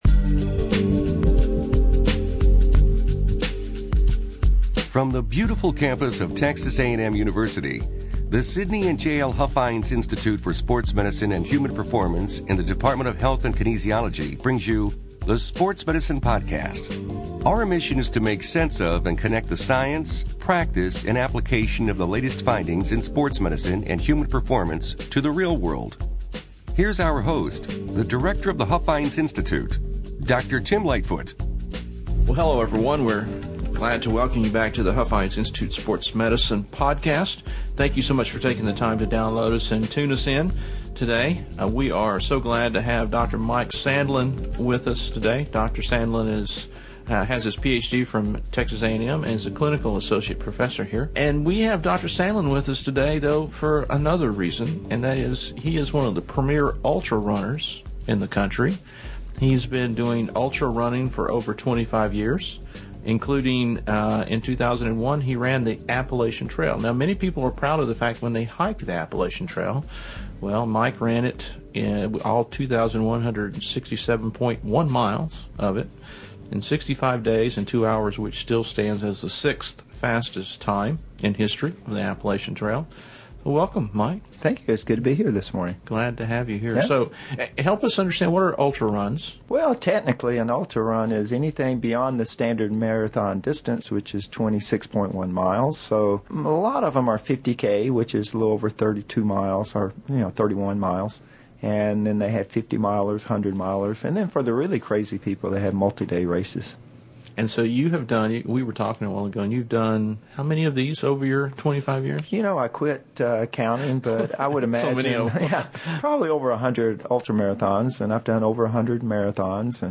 Join us to find about what it takes to run extremely long distances (more than 26 miles!). This is a great conversation filled with laughs....